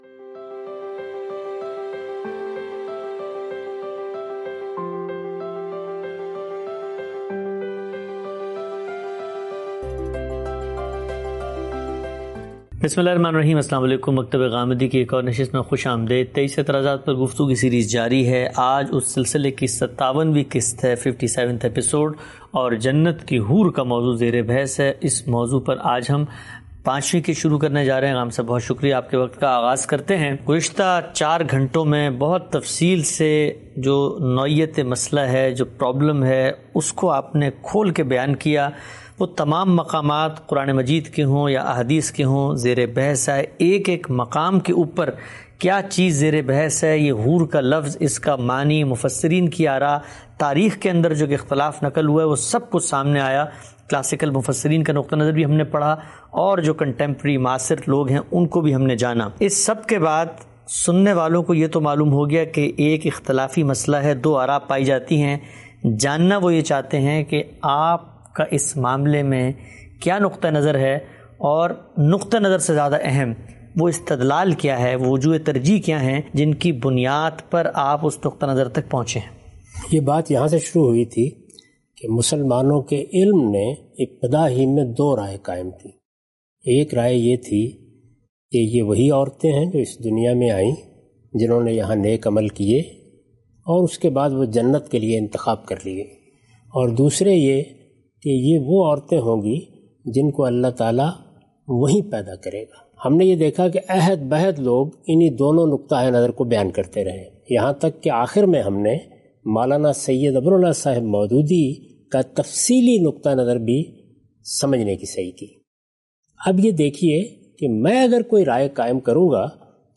In this video, Mr Ghamidi answers questions